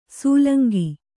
♪ sūlangi